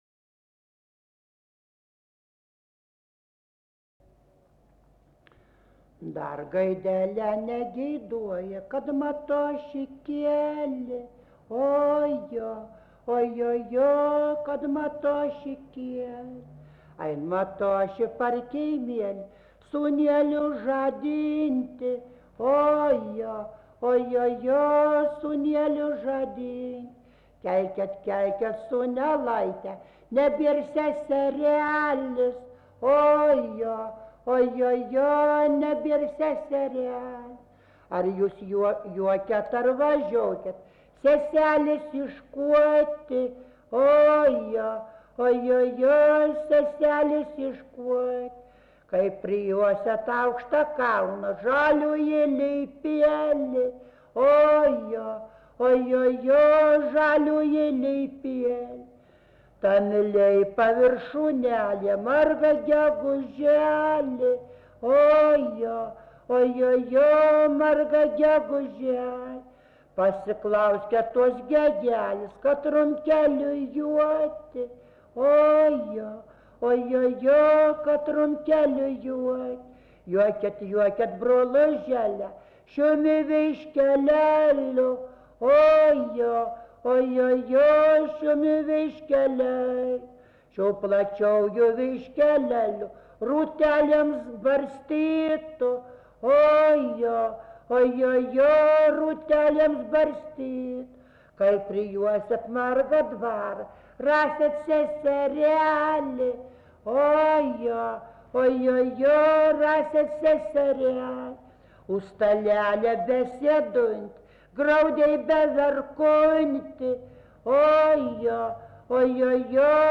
daina, vaikų
Erdvinė aprėptis Pūšiliai
Atlikimo pubūdis vokalinis